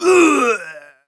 dead_1.wav